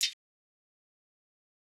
Gone Hi-Hat.wav